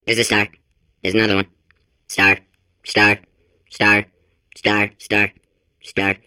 File File history File usage SpaceStarStar.wav  (WAV audio file, length 6.3 s, 706 kbps overall) This file is an audio rip from a(n) Windows game.
Portal 2/Unused Voice Lines